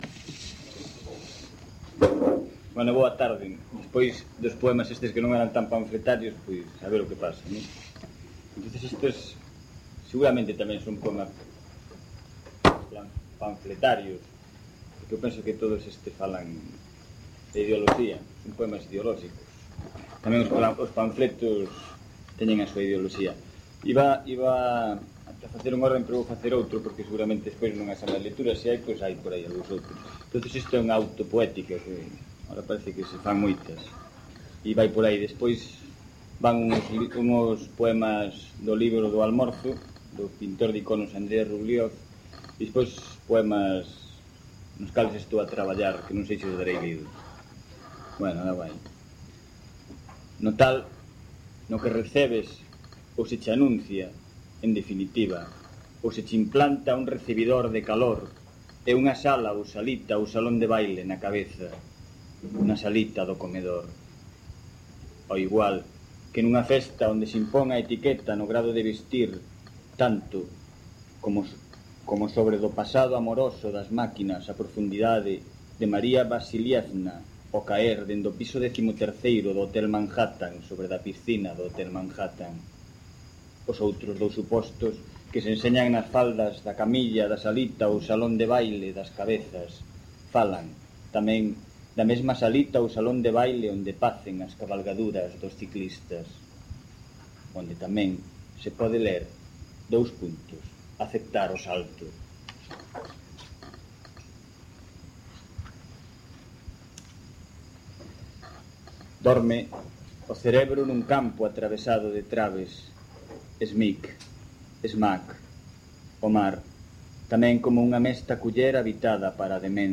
Recital no bar Pepa a Loba
Gravaci�n realizada no bar Pepa a Loba de Santiago de Compostela (r�a do Castro, 7) o 20 de xullo de 1999.